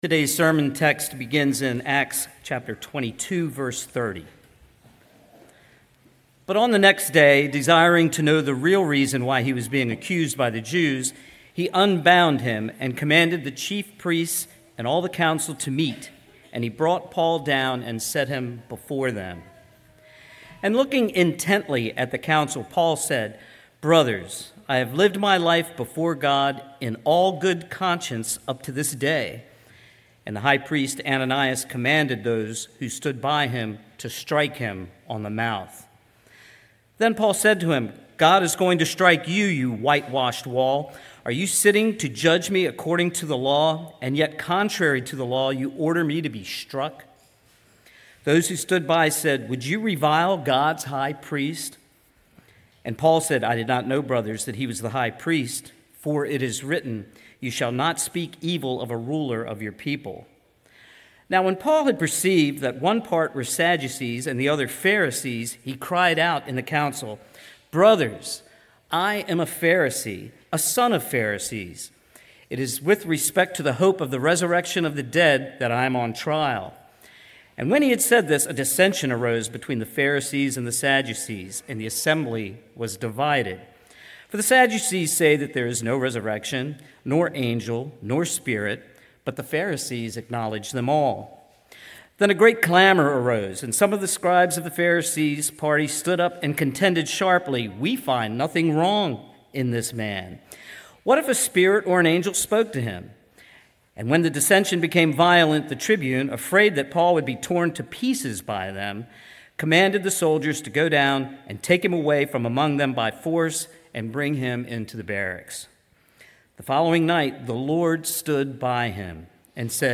sermon9.21.25.mp3